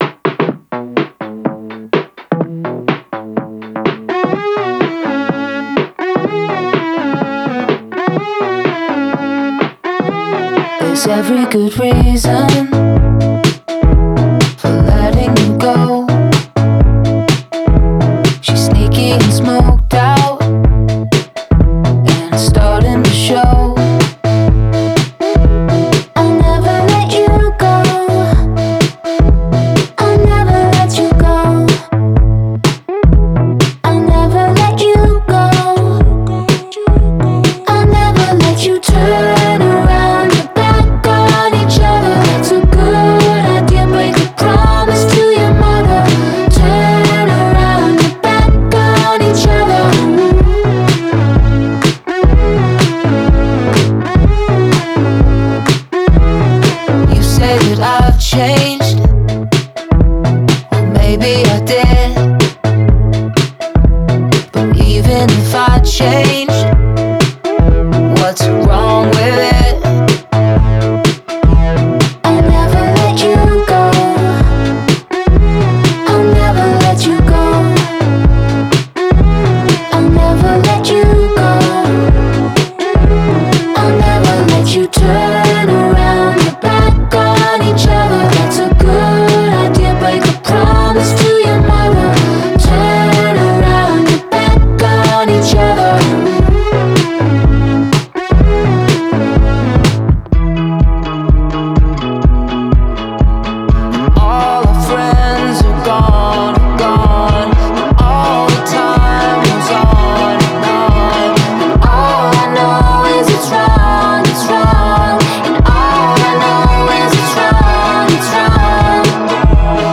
это энергичная поп-песня в жанре электронного попа